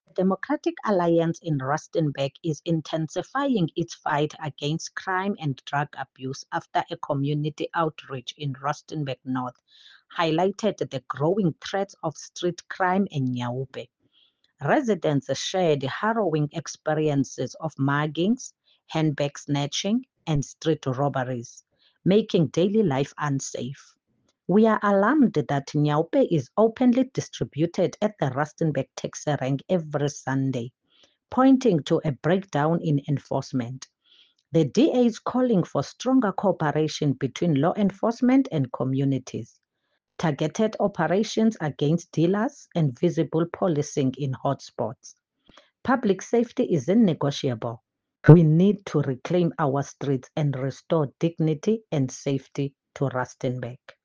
Issued by Vivian Myoli – DA PR Councillor, Rustenburg Local Municipality
Note to Broadcasters: Please find attached soundbites in